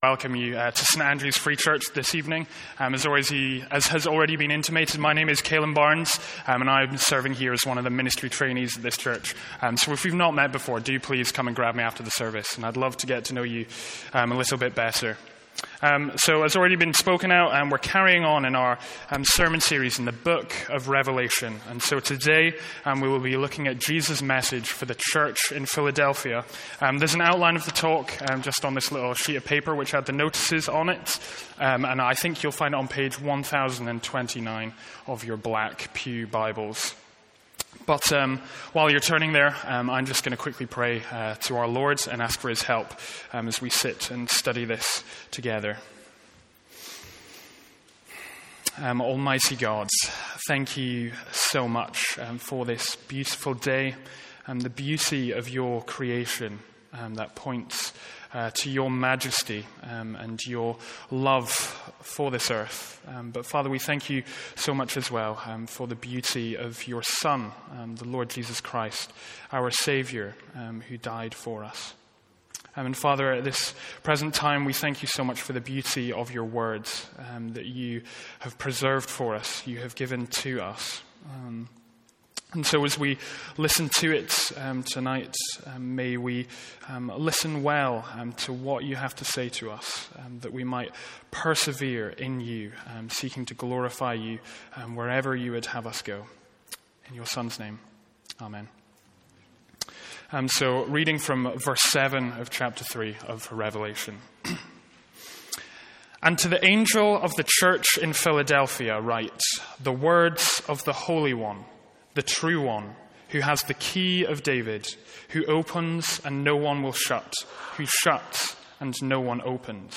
Sermons | St Andrews Free Church
From our evening series in Revelation.